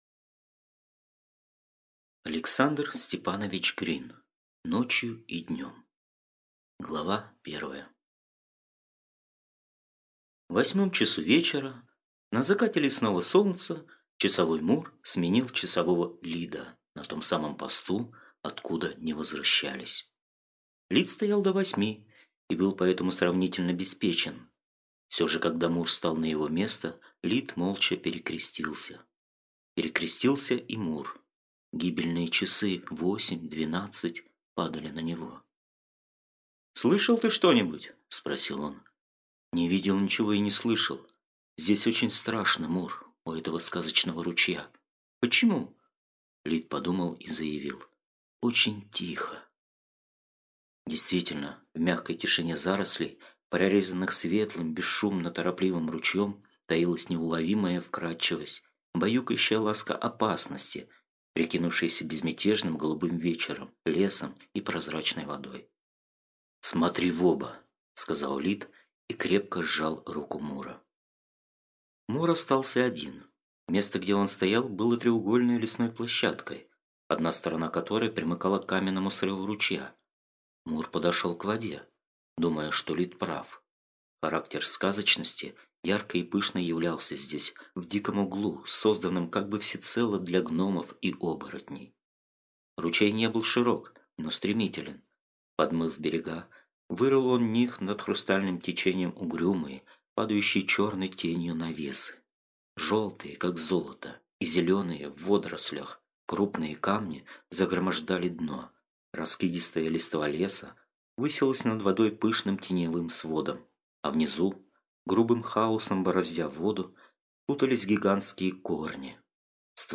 Аудиокнига Ночью и днем | Библиотека аудиокниг